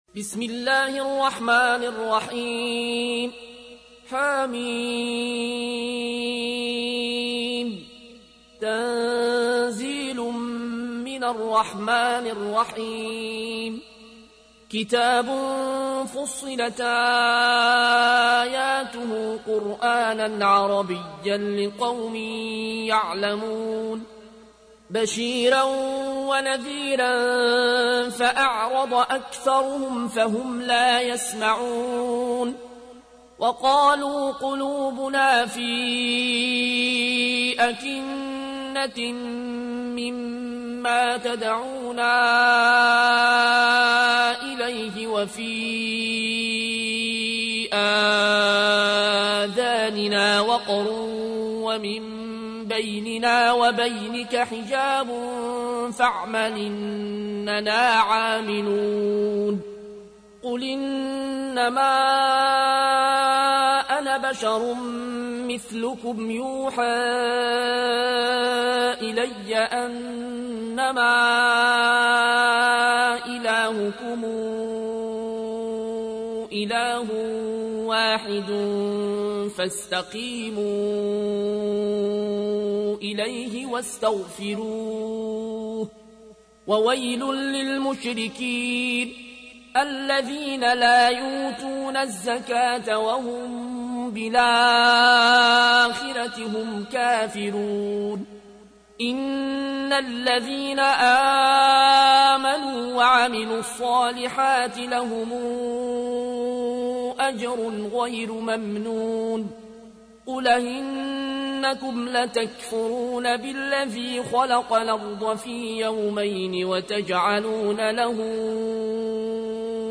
تحميل : 41. سورة فصلت / القارئ العيون الكوشي / القرآن الكريم / موقع يا حسين